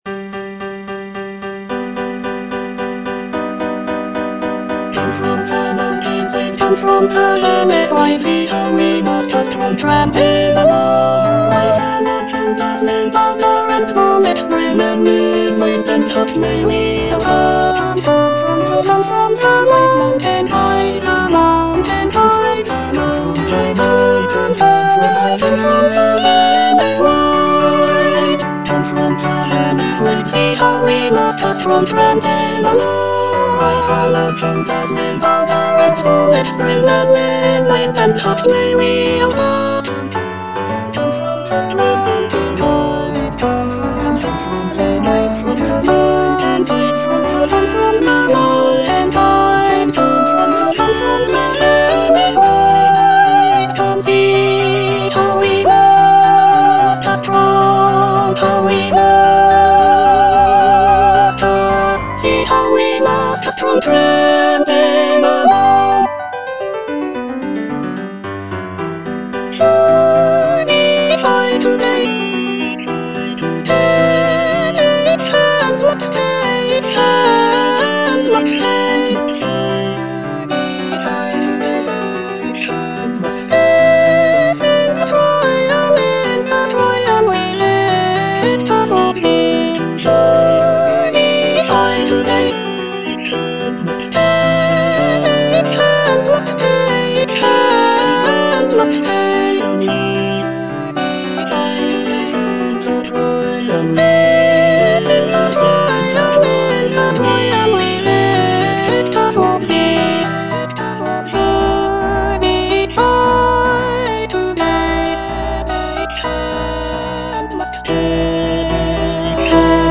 (soprano I) (Emphasised voice and other voices) Ads stop